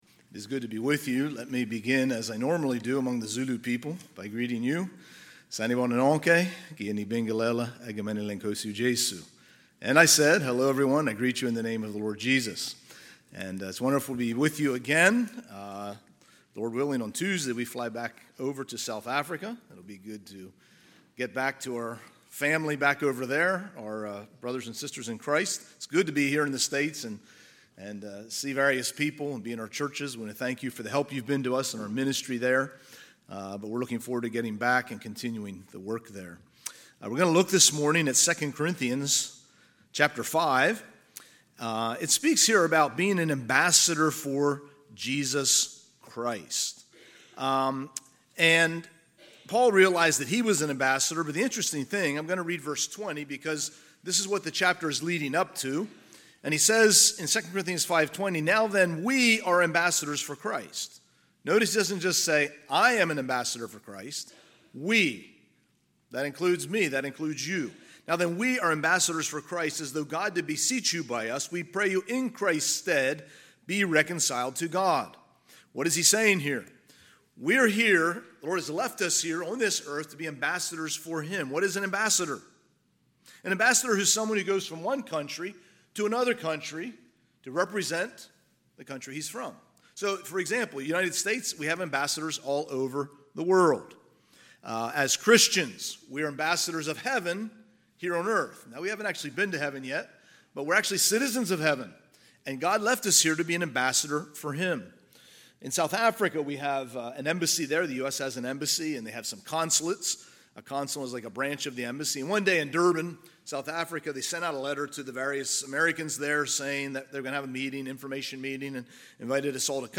Sunday, August 4, 2024 – Sunday AM
Sermons